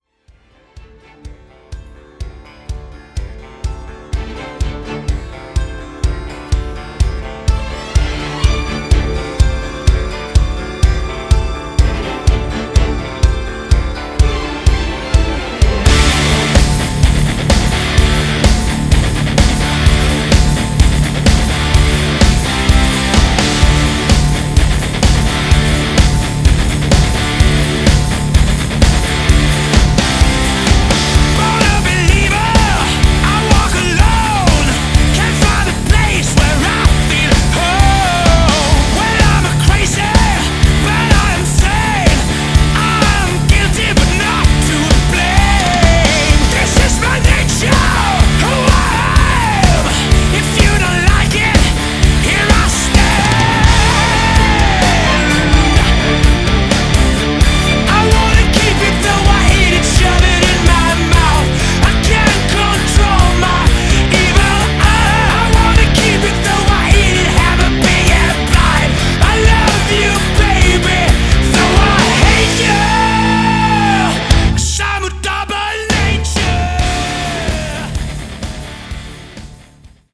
All guitars
vocals
kickdrum